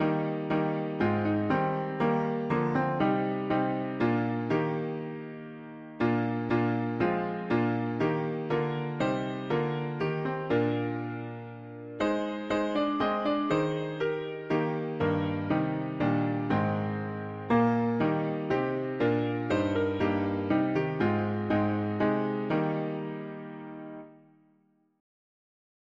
Irish melody
Key: E-flat major